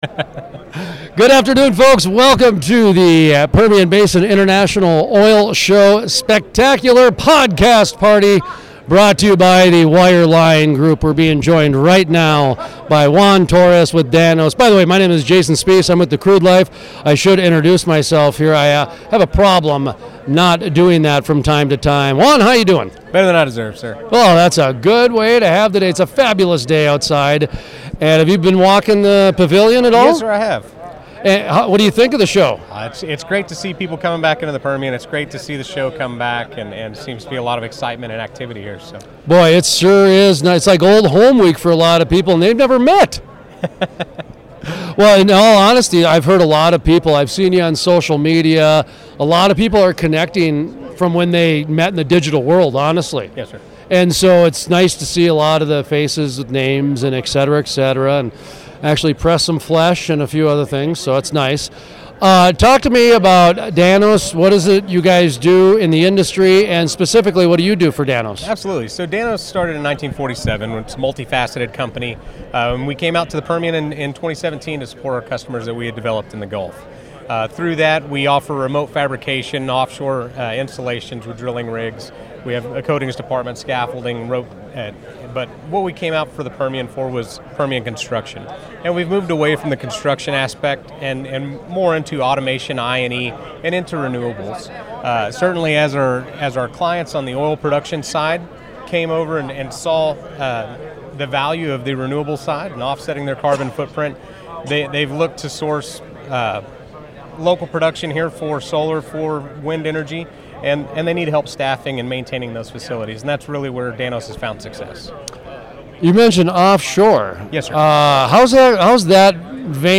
Full Length Interviews
pbios-podcast-party-off-shore-renewables-and-the-permian-play